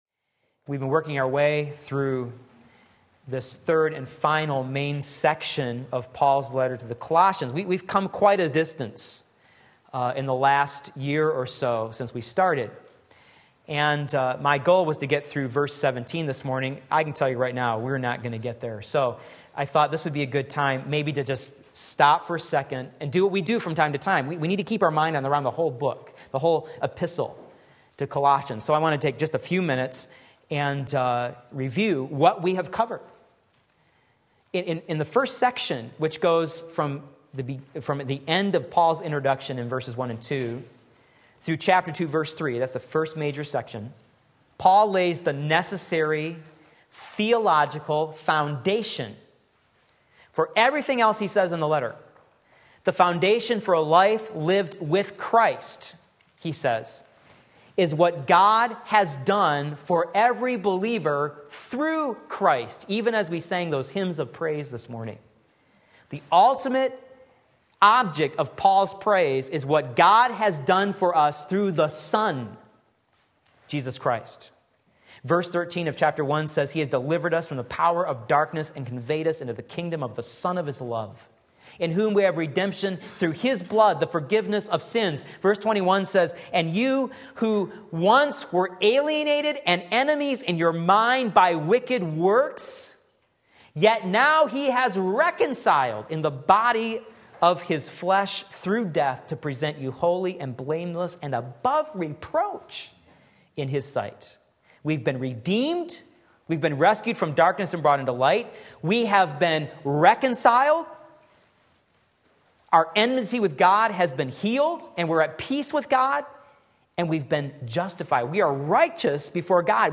Sermon Link
Part 1 Colossians 3:15-17 Sunday Morning Service